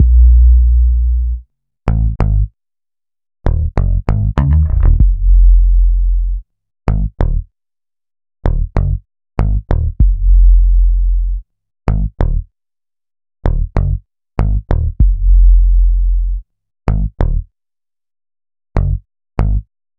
Night Rider - Deep Bass.wav